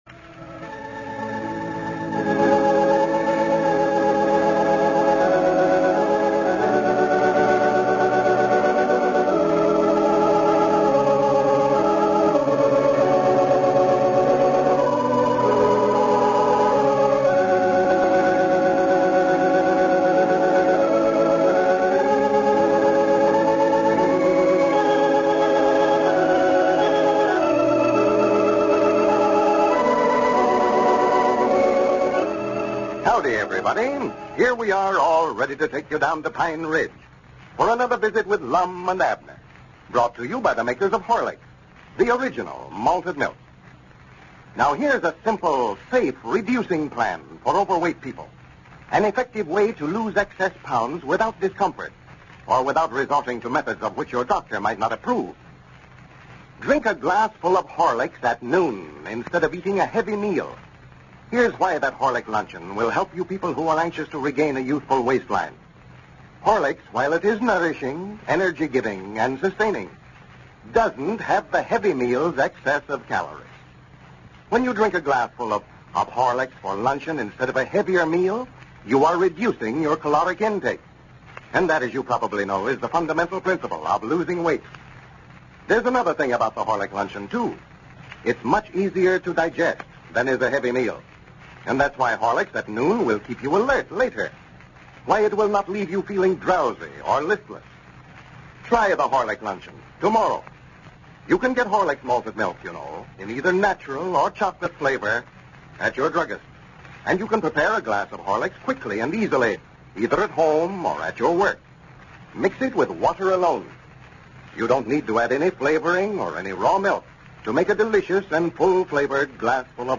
Lum and Abner! A classic radio show that brought laughter to millions of Americans from 1931 to 1954.